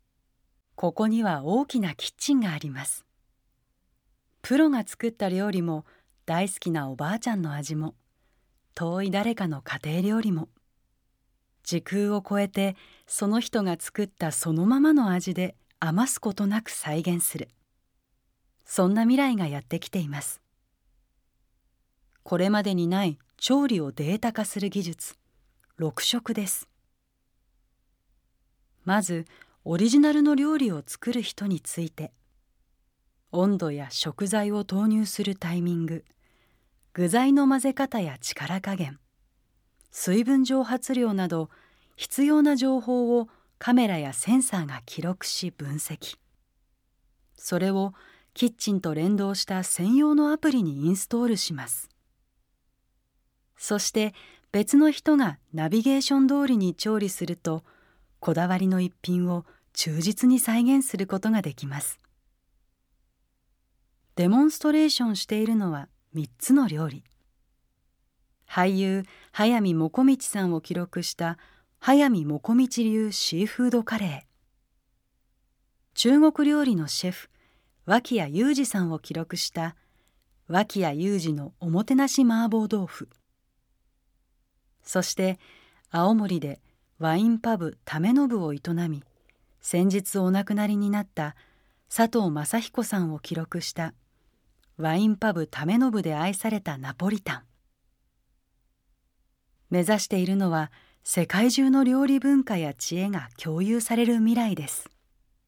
音声ガイドナレーター：宇賀なつみ